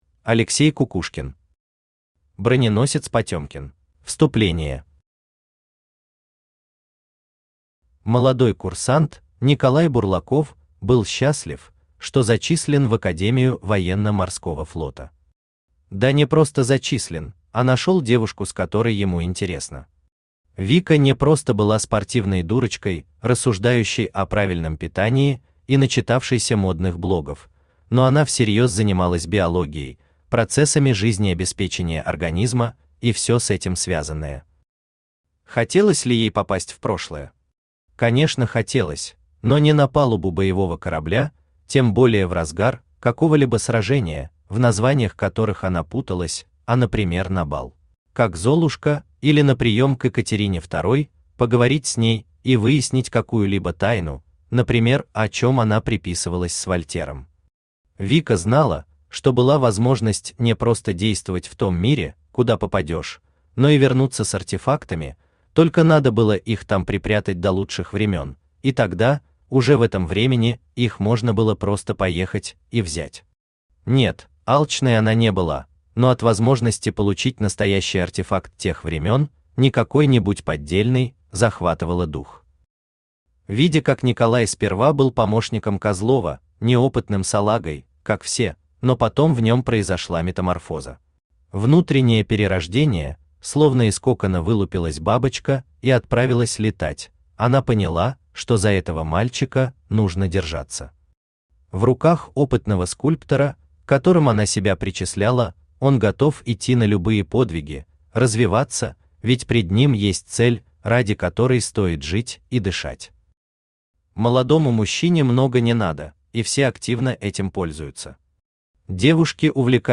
Аудиокнига Броненосец «Потемкин» | Библиотека аудиокниг
Aудиокнига Броненосец «Потемкин» Автор Алексей Николаевич Кукушкин Читает аудиокнигу Авточтец ЛитРес.